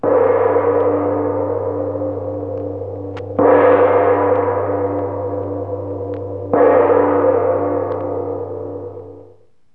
gong3.wav